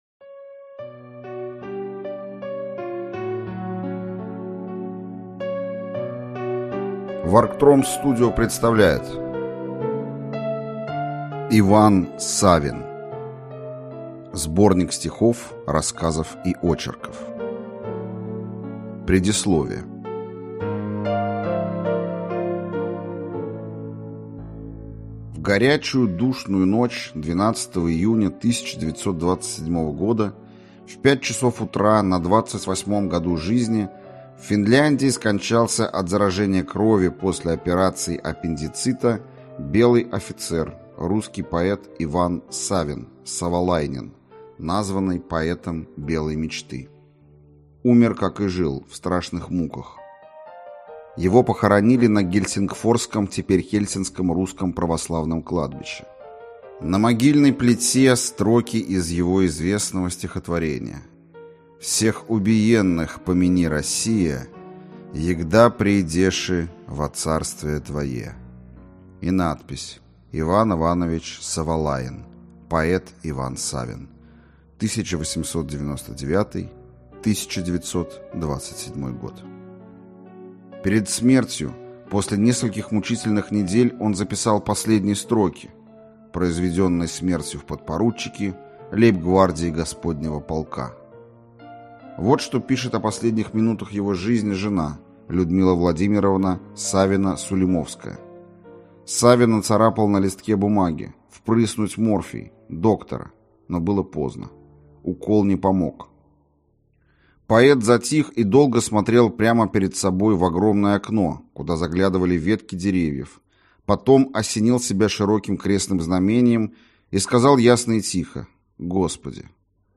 Аудиокнига Стихи и рассказы | Библиотека аудиокниг